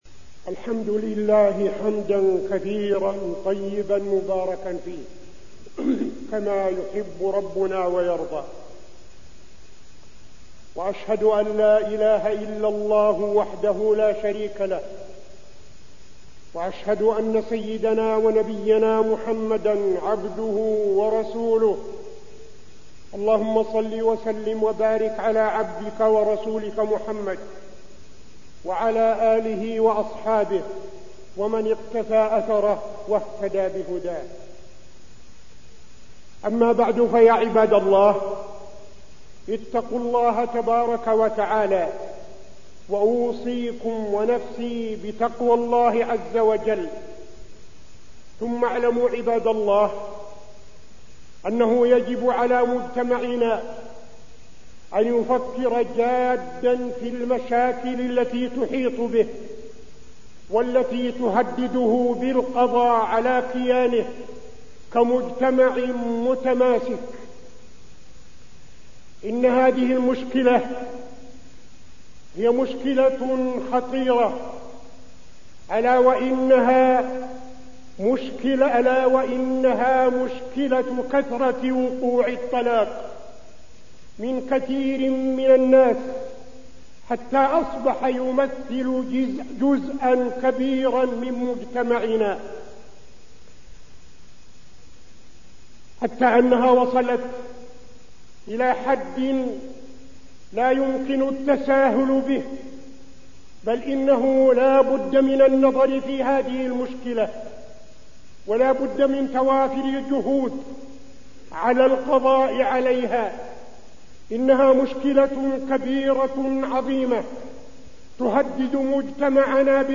تاريخ النشر ١٠ ربيع الثاني ١٤٠٤ هـ المكان: المسجد النبوي الشيخ: فضيلة الشيخ عبدالعزيز بن صالح فضيلة الشيخ عبدالعزيز بن صالح الطلاق